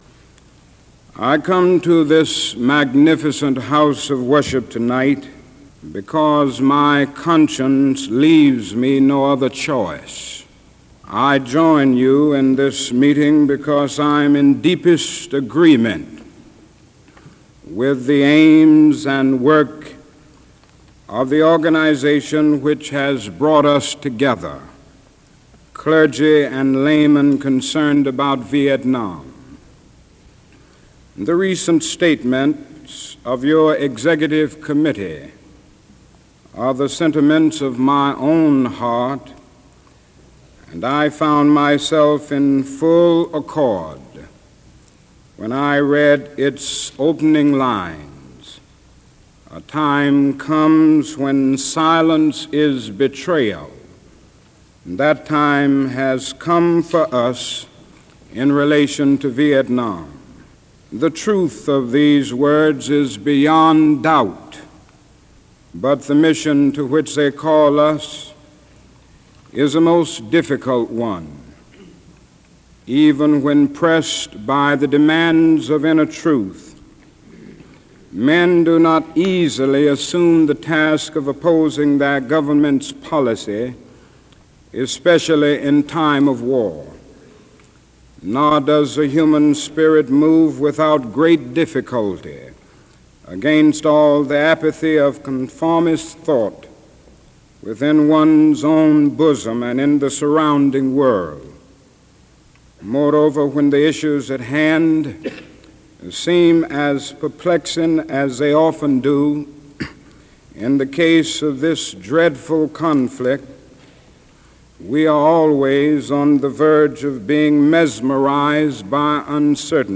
Dr. Martin Luther King - Sermon: Beyond Vietnam - April 4, 1967 - In honor of Dr. King's Birthday, a sermon he delivered at the height of the Vietnam War.
Rev. Dr. Martin Luther King – Sermon at Riverside Church – April 4, 1967 – Southern Christian Leadership Conference –